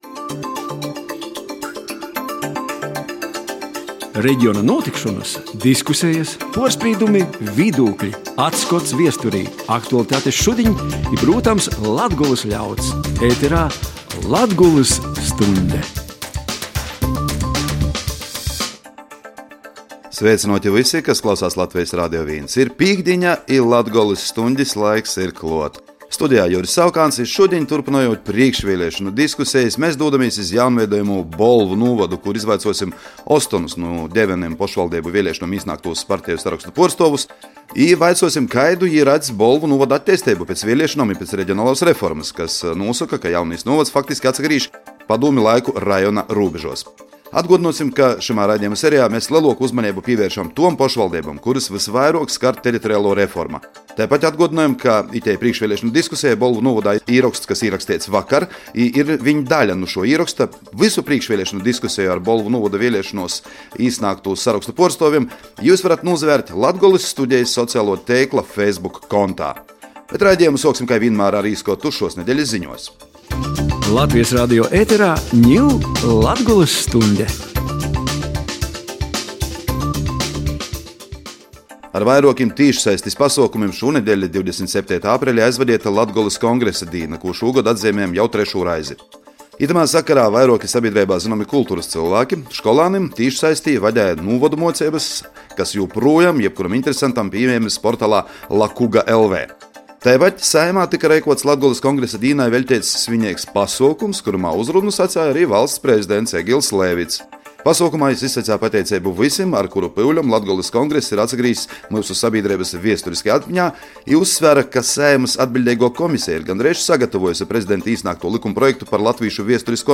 Prīškvieliešonu diskuseja jaunveidojamajā Bolvu nūvodā
Prīškvieliešonu diskusejā dasadola ostoņu politiskūs parteju ci apvīneibu lideri.